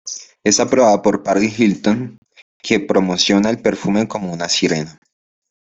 Read more Paris, the Trojan prince Frequency B2 Hyphenated as Pa‧ris Pronounced as (IPA) /ˈpaɾis/ Etymology Borrowed from Ancient Greek Πάρις In summary Borrowed from Ancient Greek Πάρις (Páris).